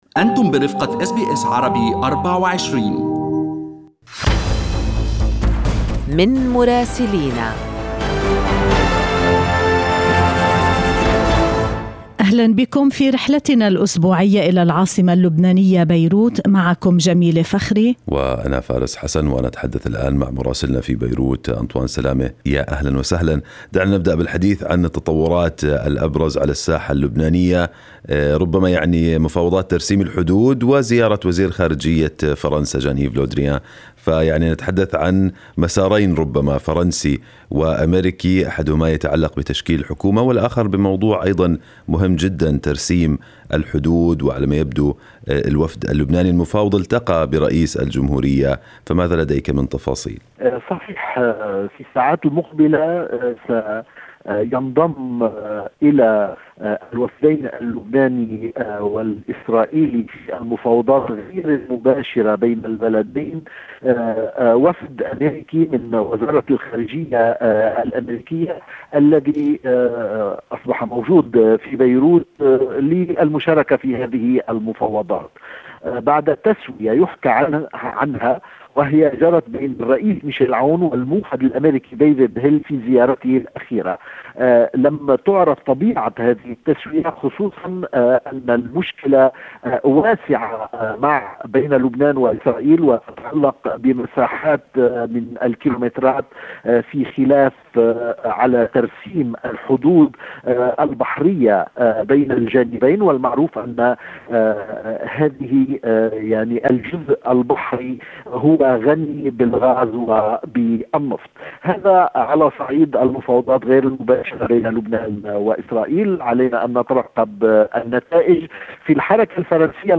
من مراسلينا: أخبار لبنان في أسبوع 4/5/2021